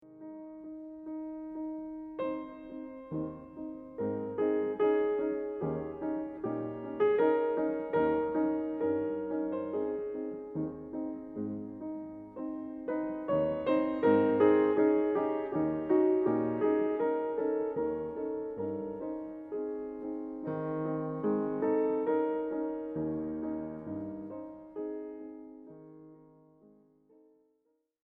At the very end, there is a sort of musical version of “and they lived happily ever after”, and it is such a gorgeous moment: